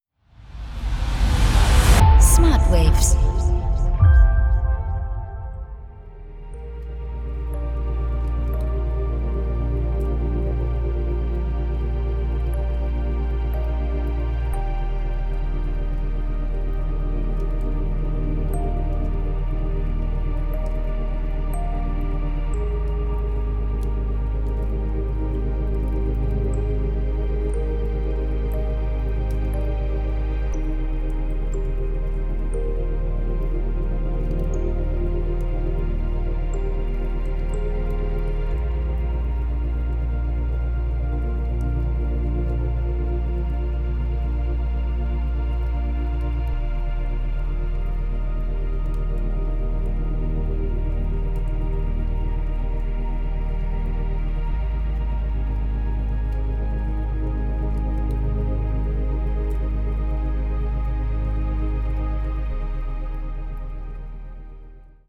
0,5-4 Hertz Delta Wellen Frequenzen